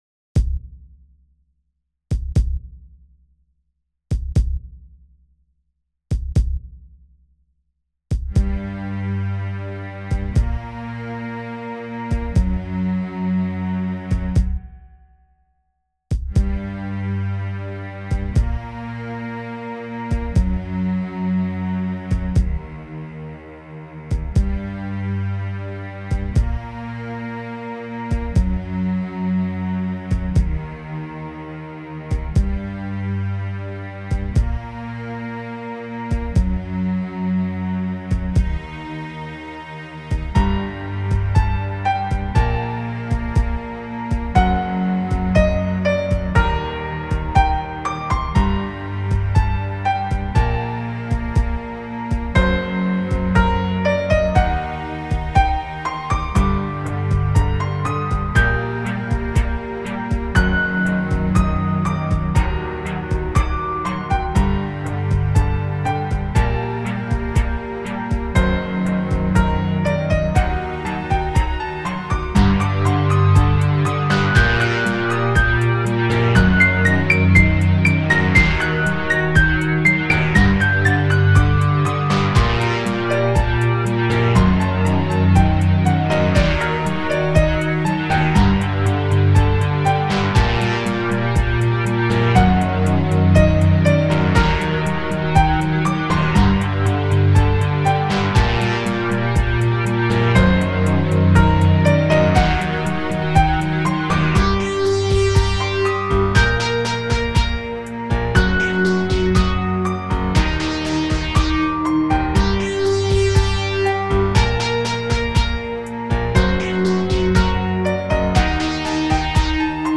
These tracks have been sequenced on Linux with Rosegarden.
All the drums are performed by Hydrogen.